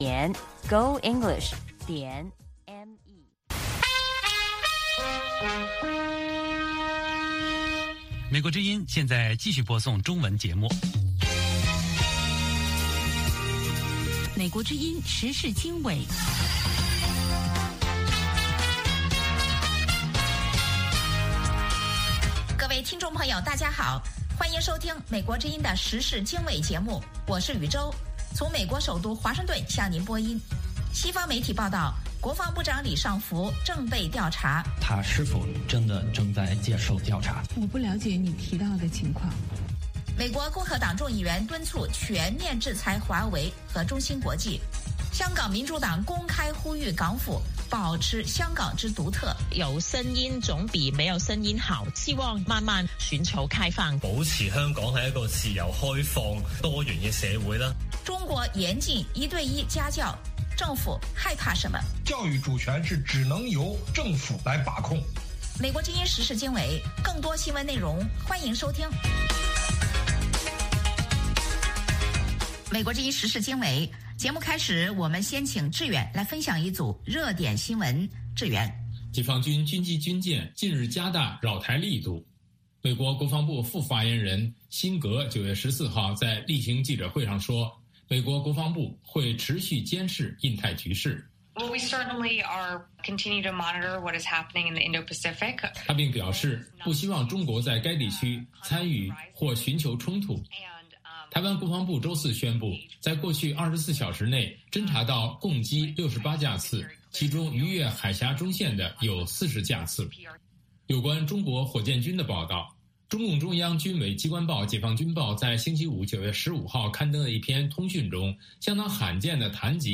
美国之音英语教学节目。